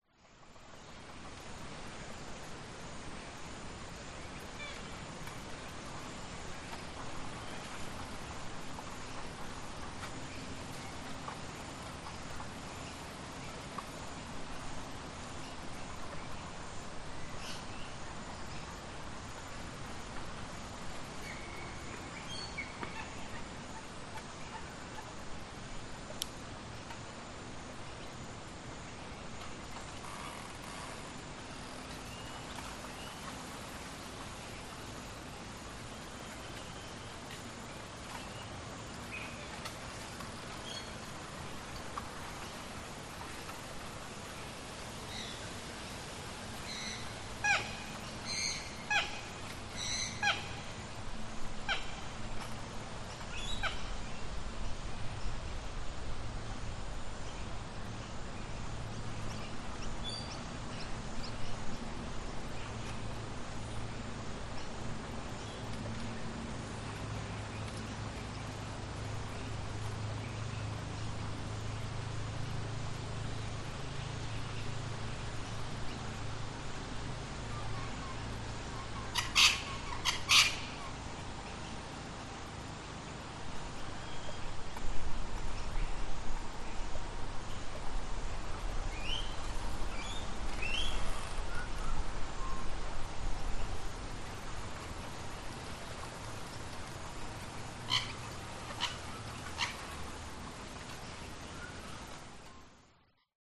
Звуки атмосферы
Парк с озером и атмосферой птиц, иногда голоса, ветер в бамбуковых деревьях, далекий трафик и пылесос для листьев в Австралии